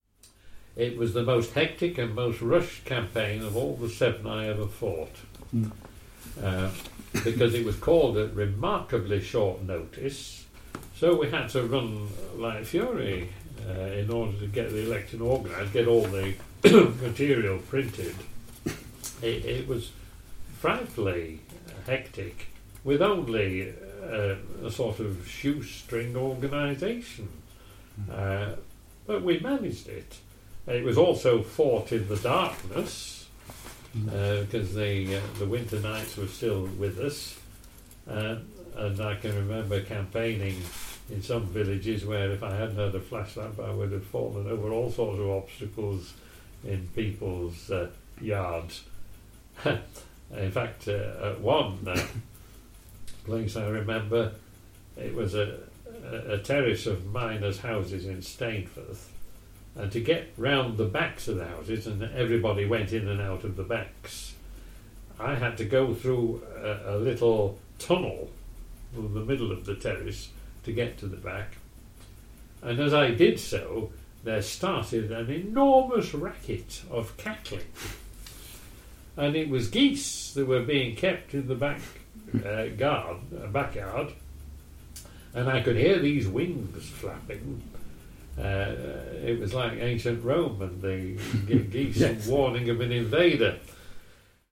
In the first in our 2017 election campaign series, we take a look back at the two elections of 1974 through the memories of our oral history project interviewees…
Our interviewees certainly remembered a miserable campaign, in bad weather, short broadcast hours and “in the dark” due to restrictions on lighting, such as in this clip from Labour’s Edmund Marshall: